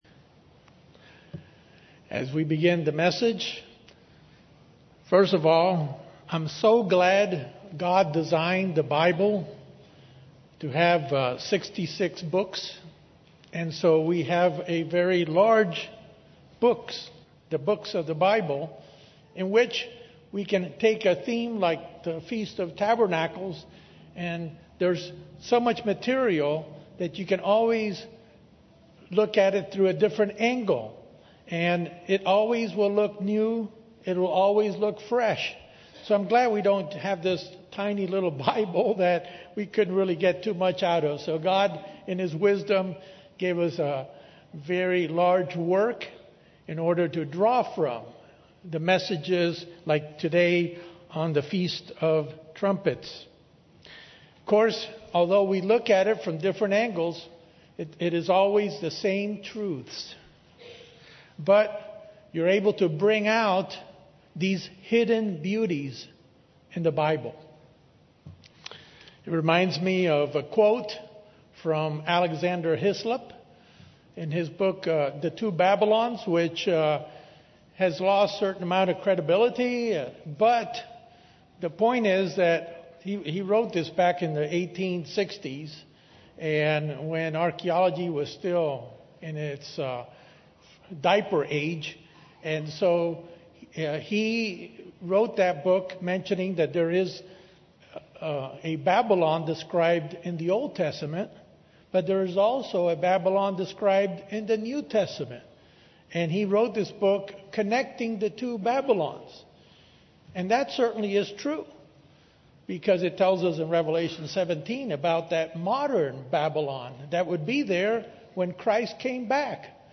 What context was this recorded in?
Given on the Feast of Trumpets, this message examines this holy day and, specifically, the meaning of the anticipated rest into which we may enter - as described in the book of Hebrews.